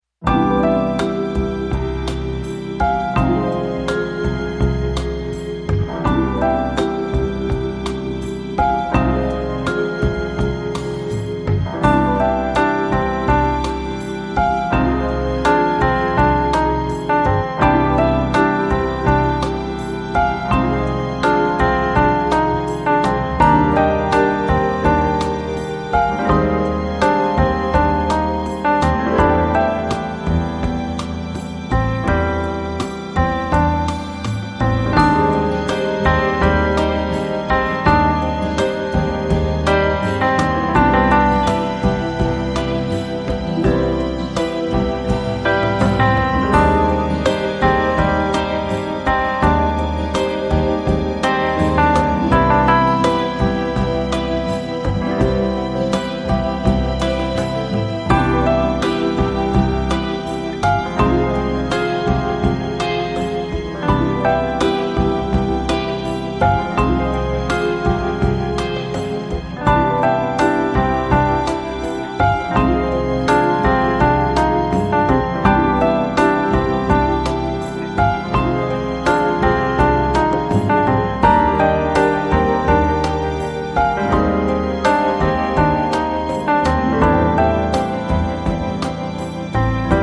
boasts strings, vibraphone, a female choir and tenor sax